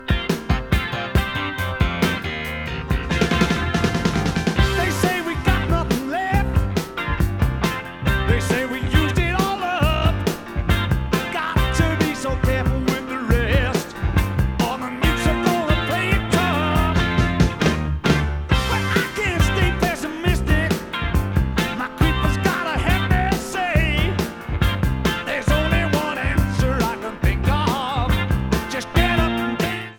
Before and After Remastering
* Minus 4.0 db Bass, Plus 2.0 db Treble